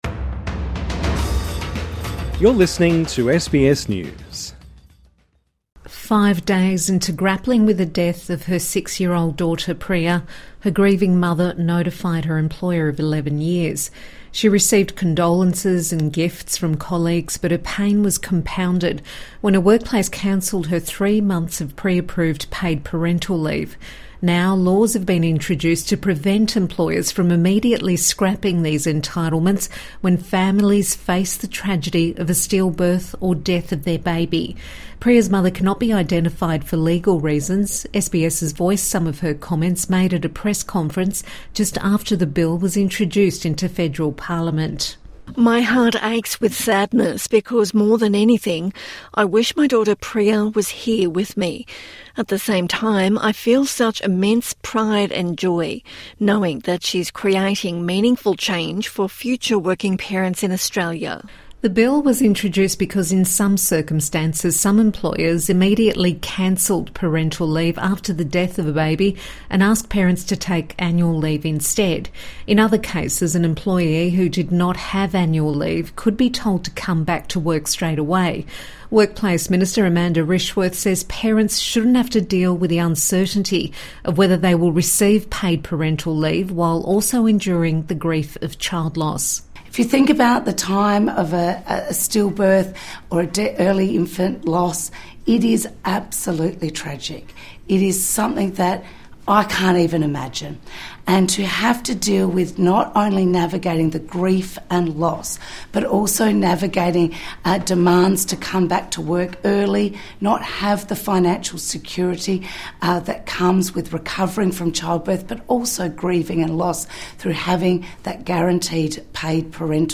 SBS has voiced some of her comments made a press conference just after the bill was introduced into federal parliament.